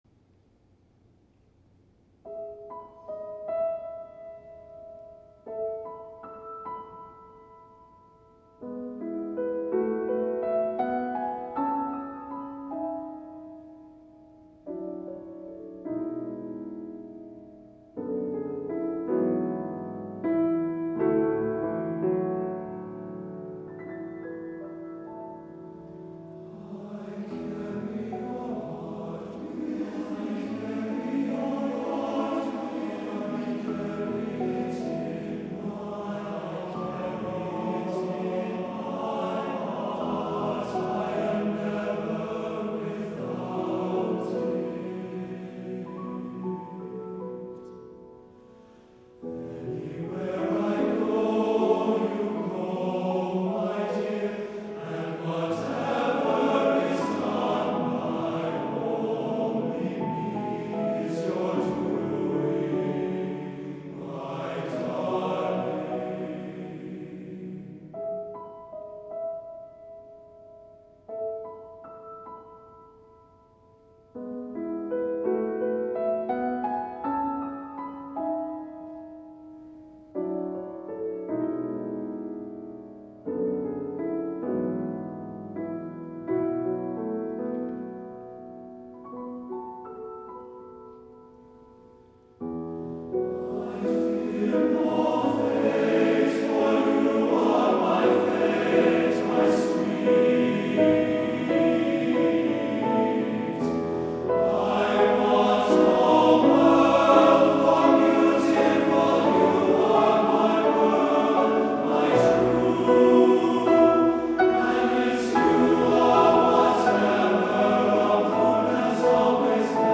Accompaniment:      With Piano
Music Category:      Choral